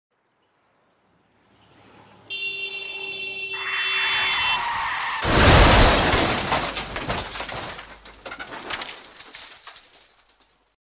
Звуки аварий автомобилей
На этой странице собраны реалистичные звуки автомобильных аварий: резкое торможение, столкновения, переворачивающиеся машины и другие тревожные моменты.
Звуковой сигнал при аварии